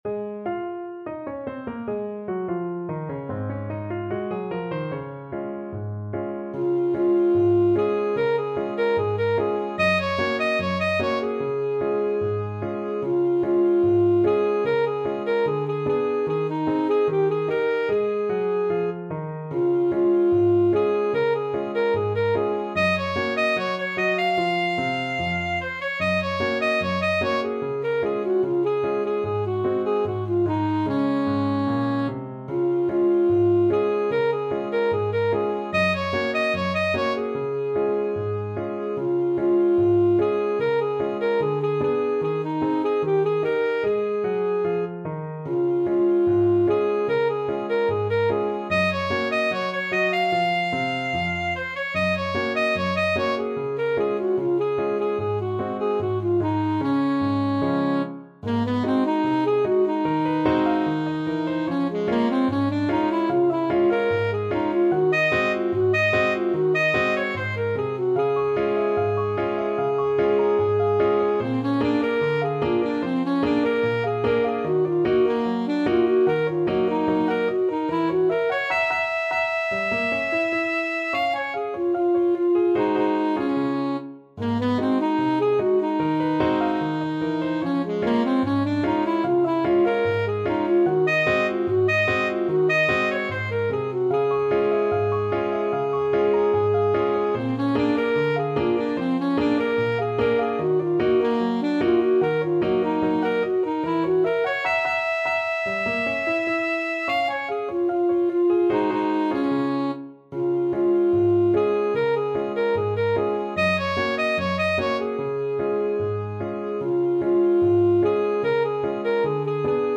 Alto Saxophone
2/4 (View more 2/4 Music)
Db major (Sounding Pitch) Bb major (Alto Saxophone in Eb) (View more Db major Music for Saxophone )
Not Fast = 74
Jazz (View more Jazz Saxophone Music)